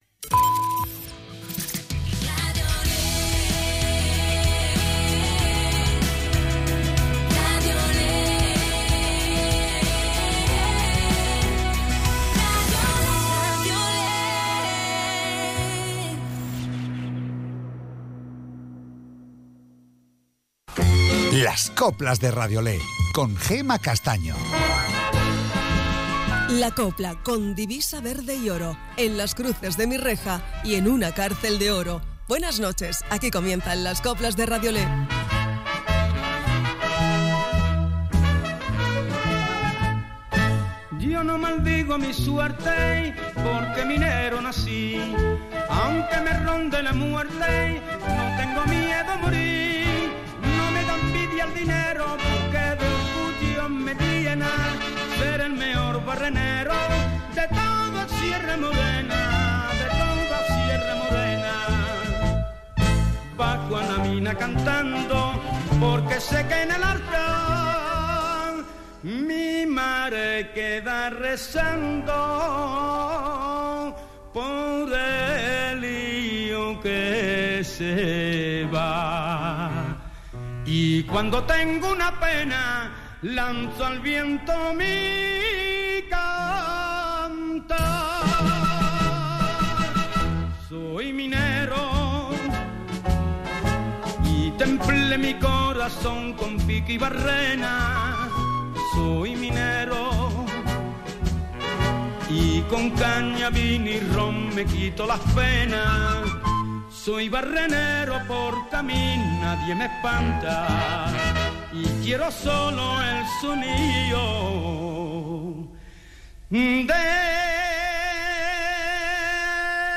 Las mejoras coplas de hoy y de siempre